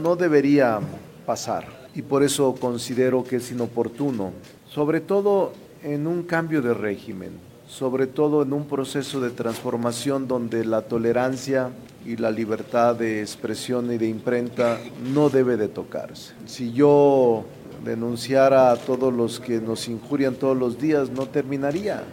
Después en conferencia de prensa, el político de Zacatecas, reiteró que esta reforma no debe pasar.